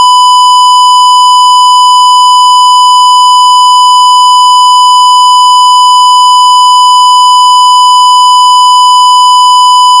square_gaussian.wav